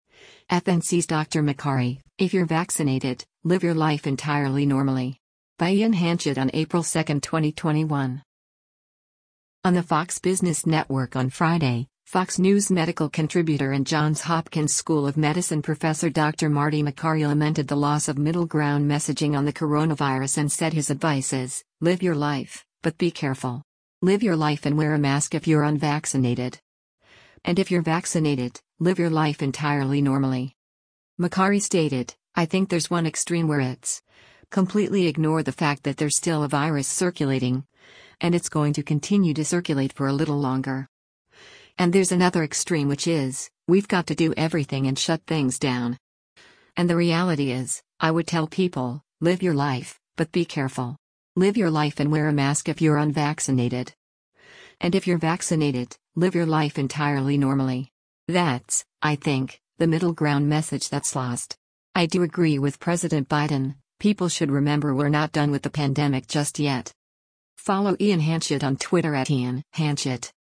On the Fox Business Network on Friday, Fox News Medical Contributor and Johns Hopkins School of Medicine Professor Dr. Marty Makary lamented the loss of middle ground messaging on the coronavirus and said his advice is, “live your life, but be careful. Live your life and wear a mask if you’re unvaccinated. And if you’re vaccinated, live your life entirely normally.”